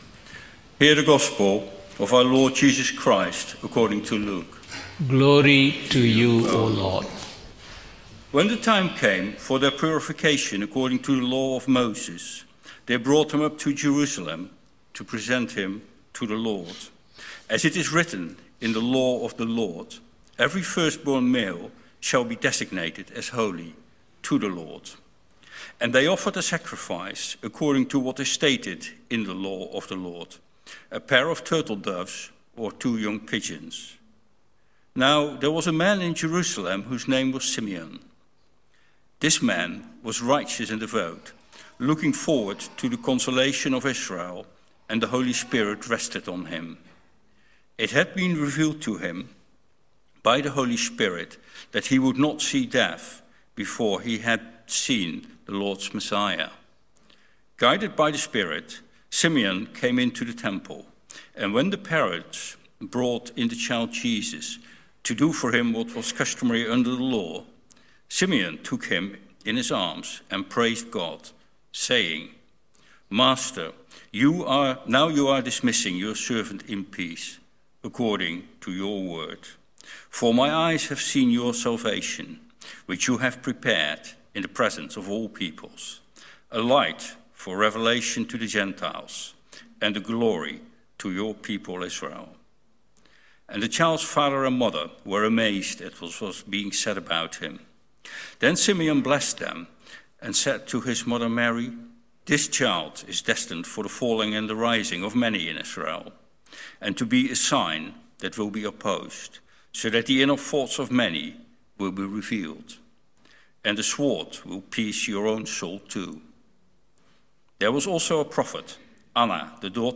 1 Sermon – January 28, 2024 22:05